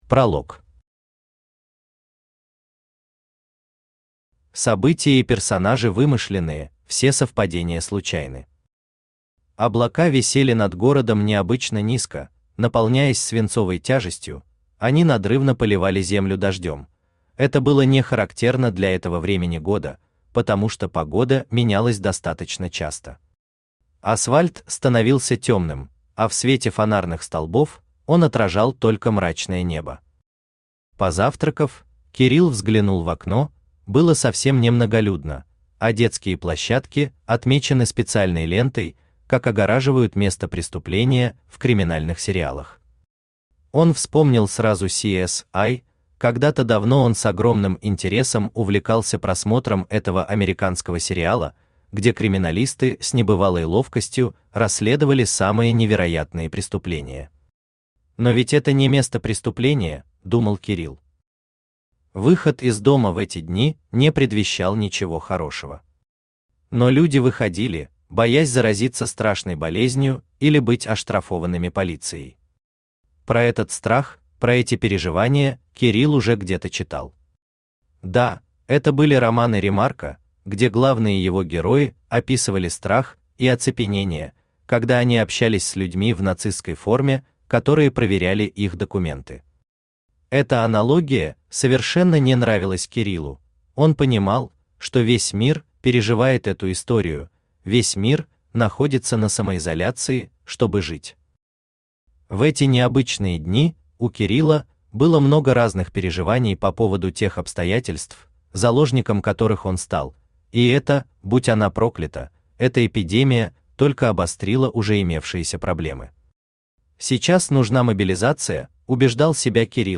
Практикум антистрессового поведения Автор Фрой Зигмундович Читает аудиокнигу Авточтец ЛитРес.